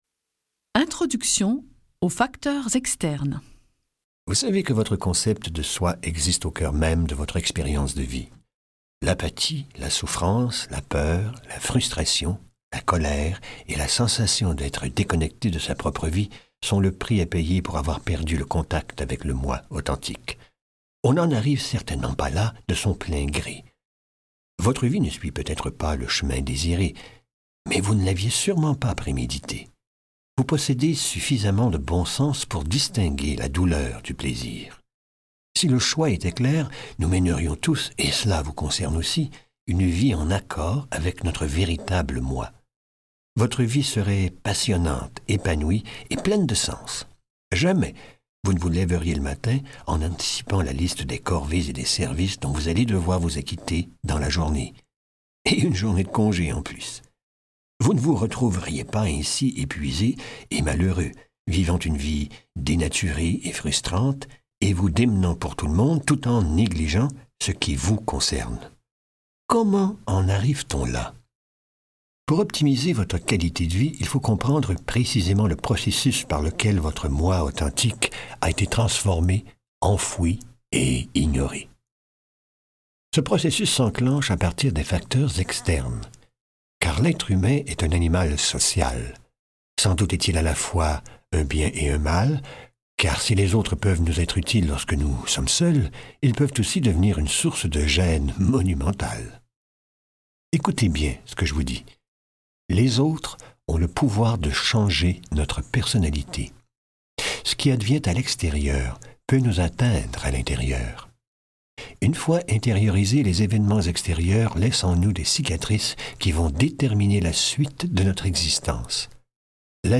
je découvre un extrait - Et moi alors ? de Philip McGraw